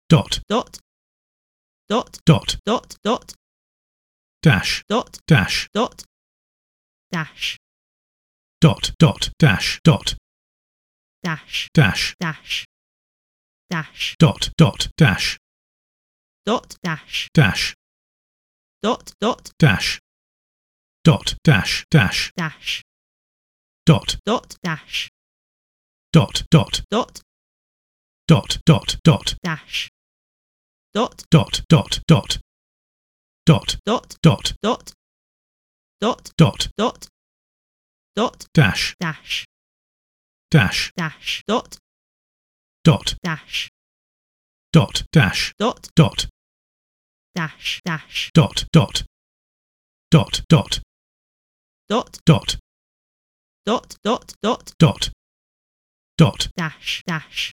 • The dots and dashes are indeed Morse code.
• The male and female voices are encoding something separately to the Morse code.
The low, male voice represents 0, the high, female voice represents 1.